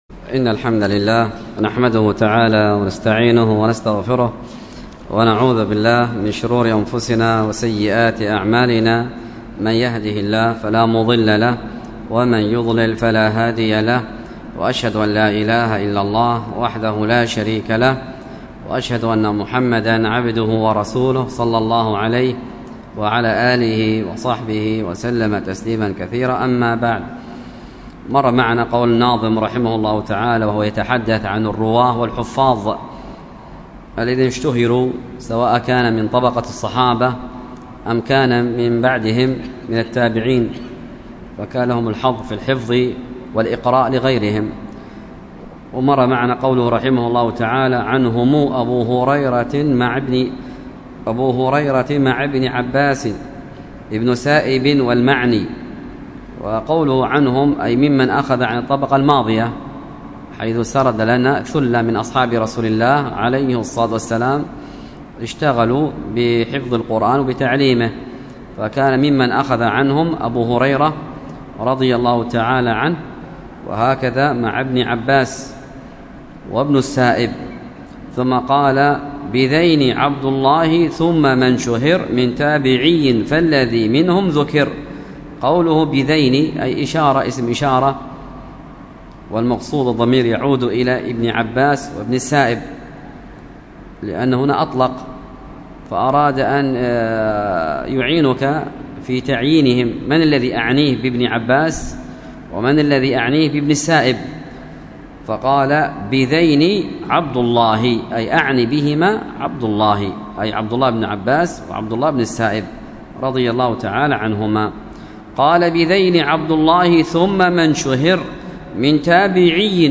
الدرس في التعليقات على شرح العقيدة الطحاوية 67، ألقاها